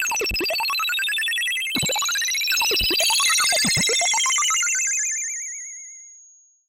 Звуки фантастики
Электронные сигналы на дисплее